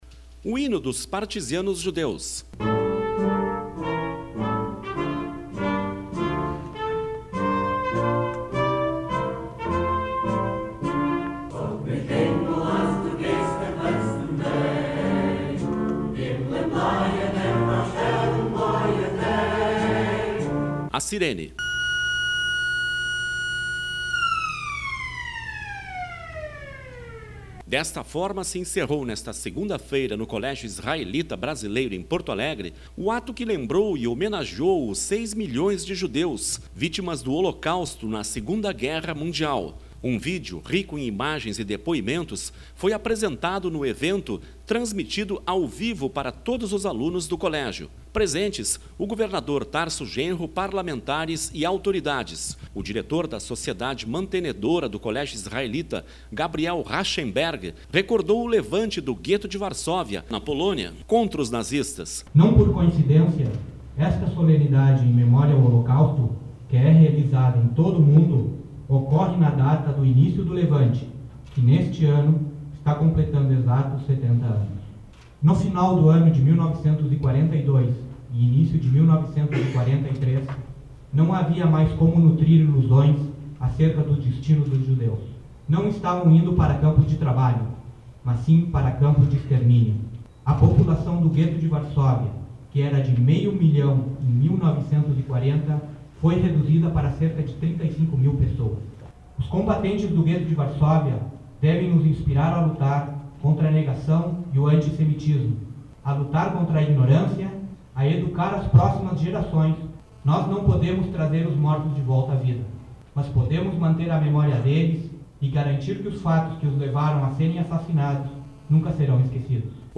Nesta segunda-feira (8) no Colégio Israelita Brasileiro, em Porto Alegre, foi realizado ato que lembrou e homenageou os 6 milhões de judeus, vítimas do holocausto na II Guerra Mundial.